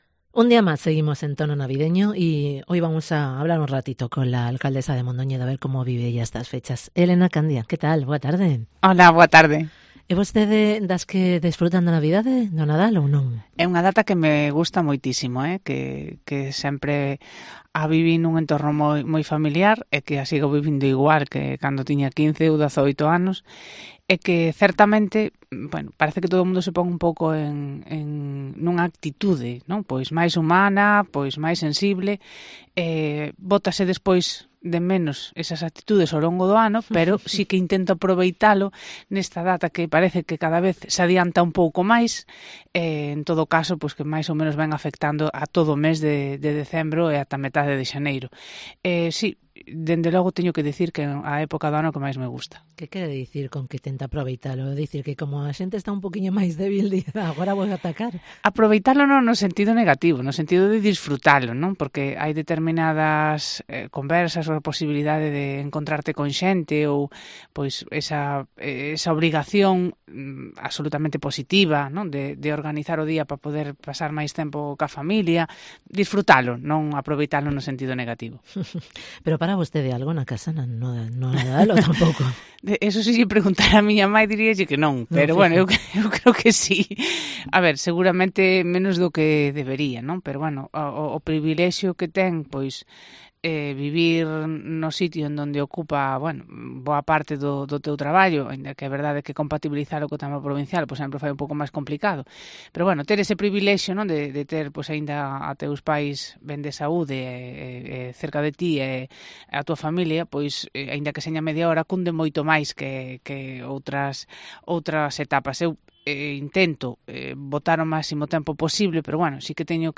ENTREVISTA con Elena Candia